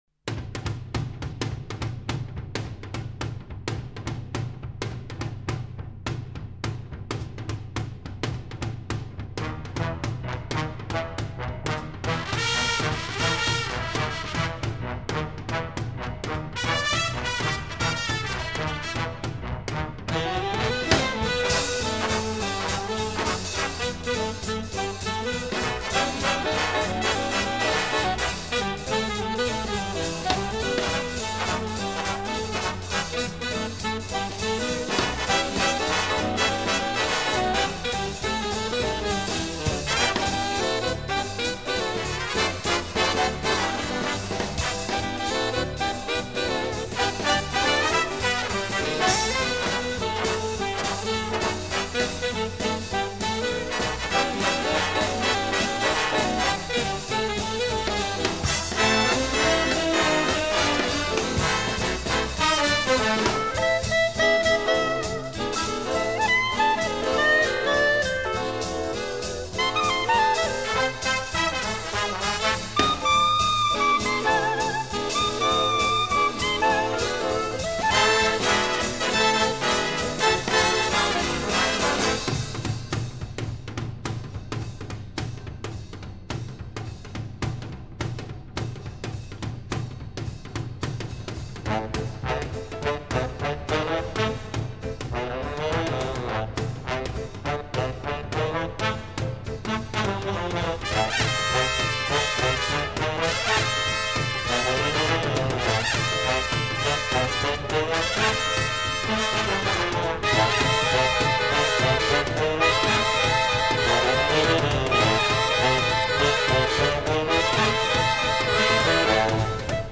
музыка и Big Band
Великолепный джазовый стандарт.